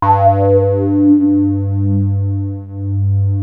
JUP 8 G3 7.wav